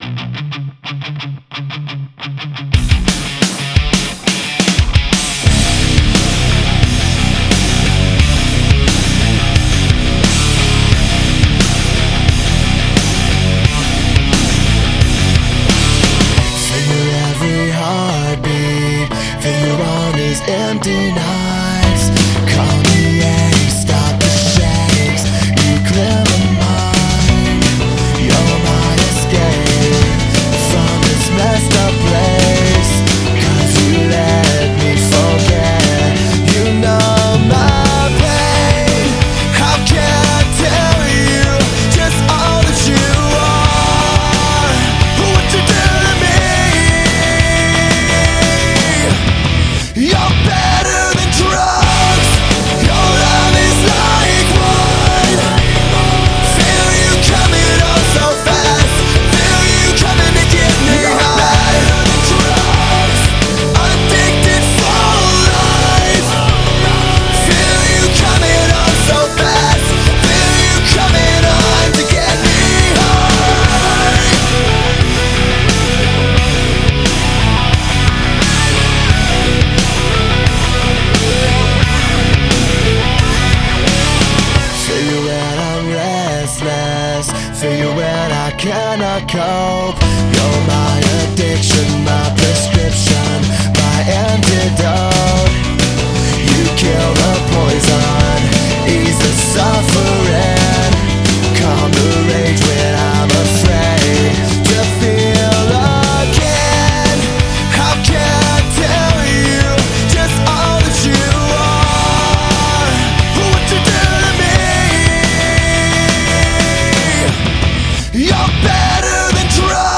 rock music